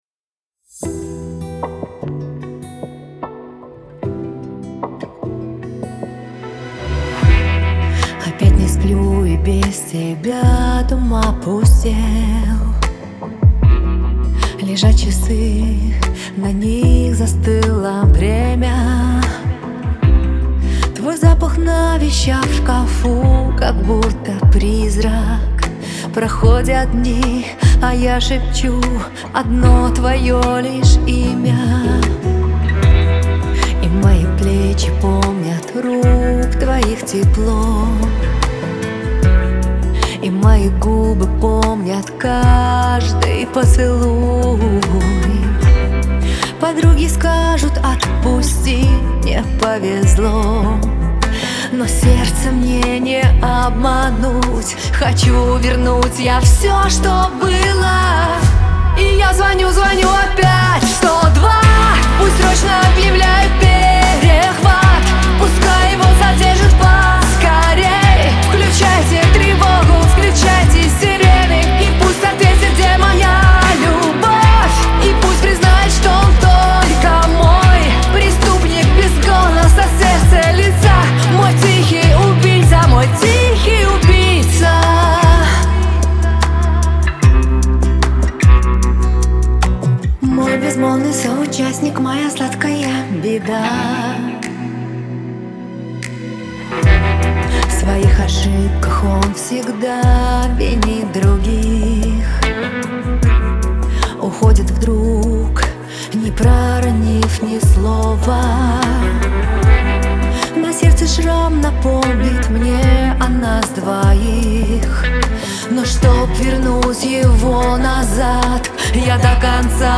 грусть , pop
диско
эстрада